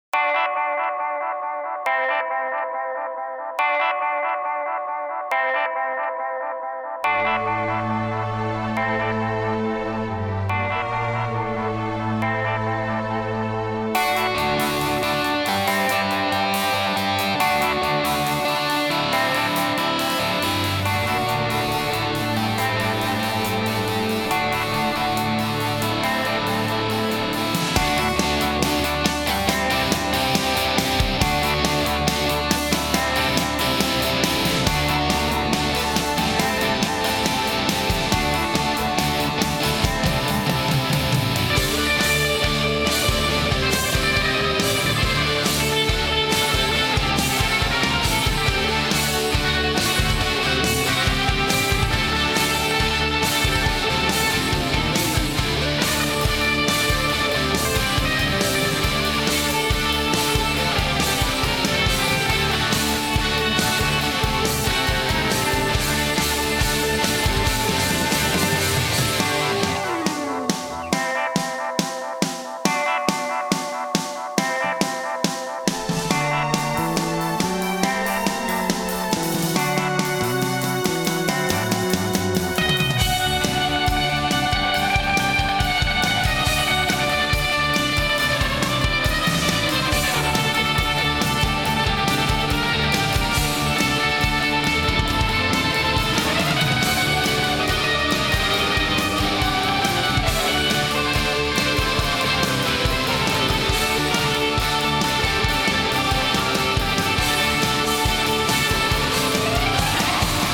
Eastern rock and surf
Guitar pop tracks